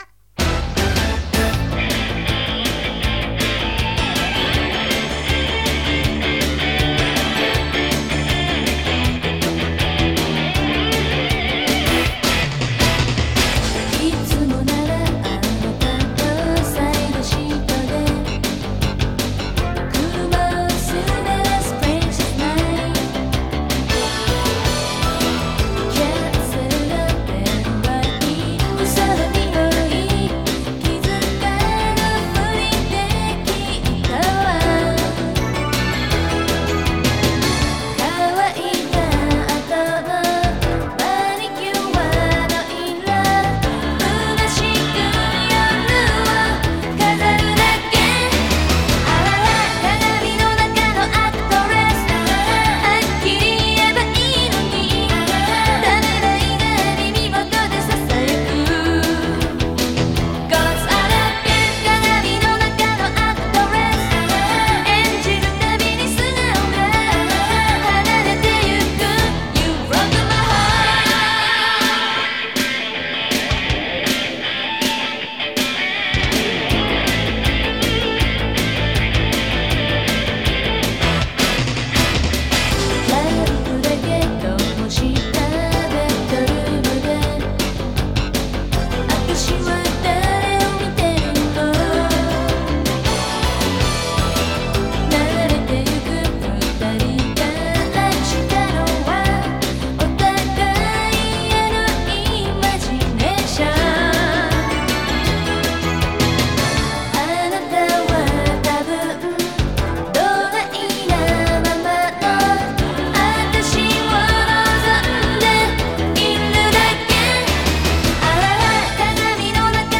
Et ça tombe d'autant mieux que ça faisait un moment que je cherchais ladite traduction, après avoir découvert ces dramas dans le Singing Heart 2.